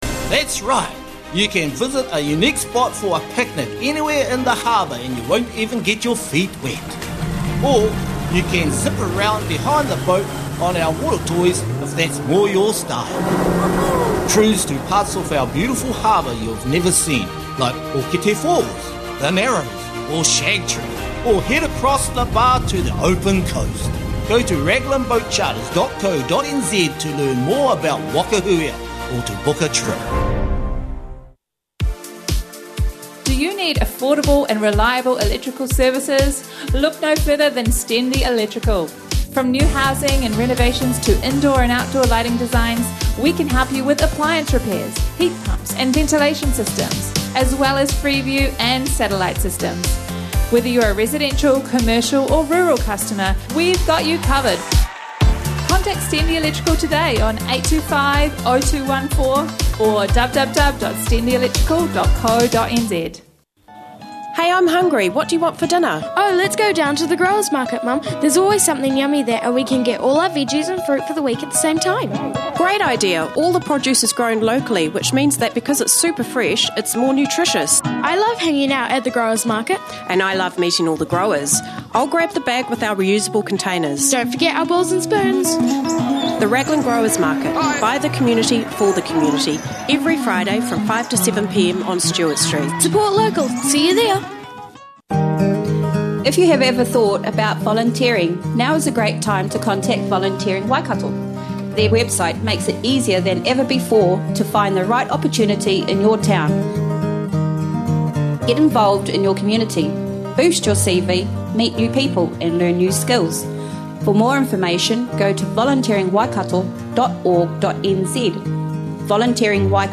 Another week of environment and news, with a close look at the bus report and its faults, a mention of the Radio's 30th on Friday, busking on Saturday and interviews from the Morning Show about environment and recycling.